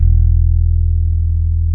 E1 1 F.BASS.wav